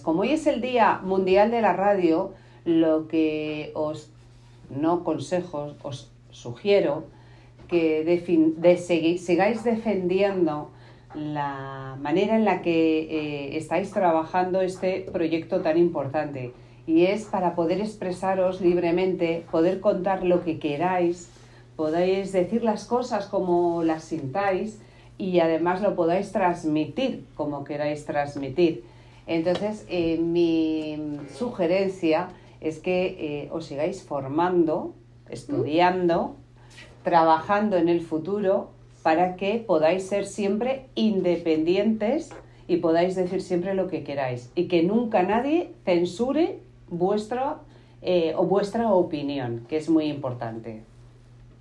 Declaración de la concejala de Chamartín, Yolanda Estrada.mp3